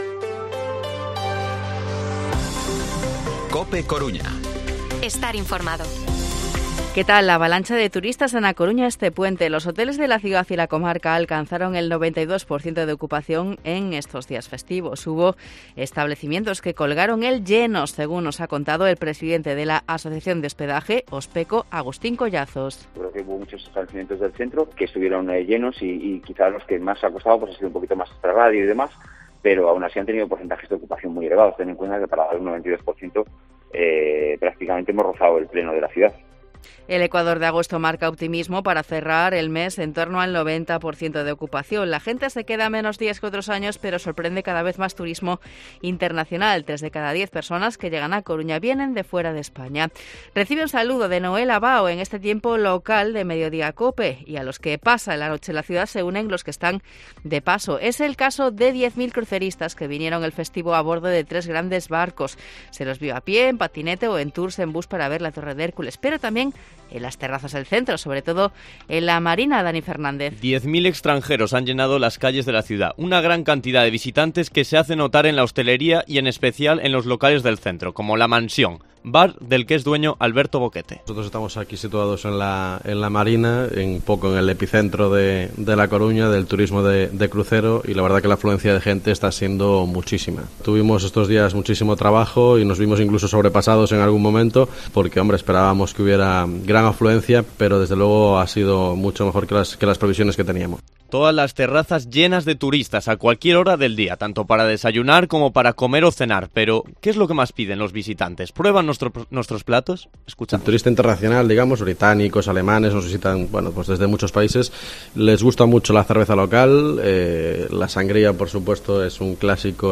Informativo Mediodía COPE Coruña miércoles, 16 de agosto de 2023 14:20-14:30